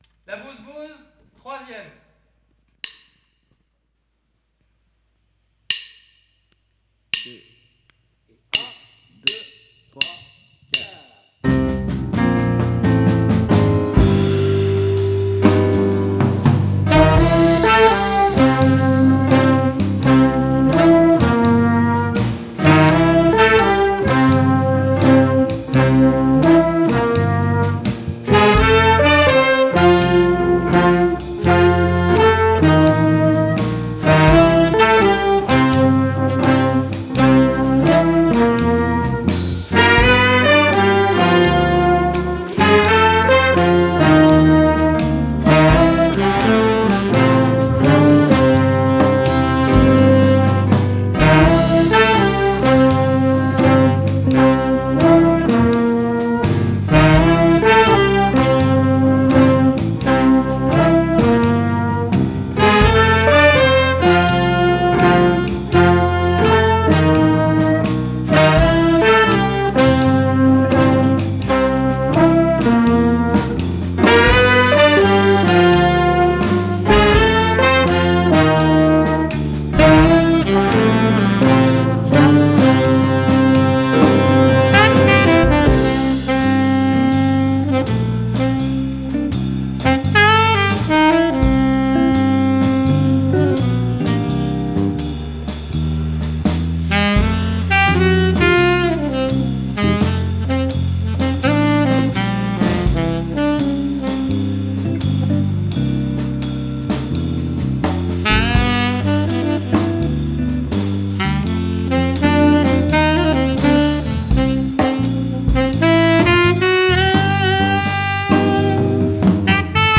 Voici 3 titres originaux enregistrés « à distance » par les profs ainsi que des bandes son qui vous permettrons de « virer » un prof et de prendre la place!